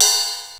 DMX RIDE 1.wav